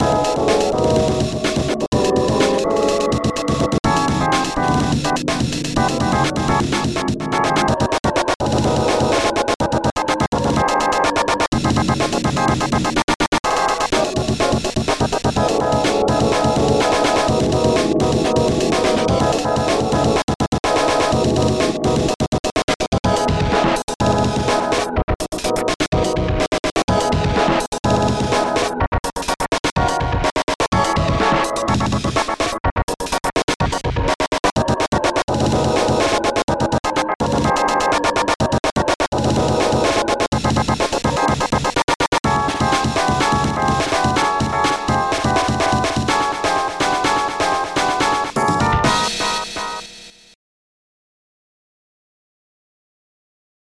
be warned like most ofthis shit is just dnb/jungle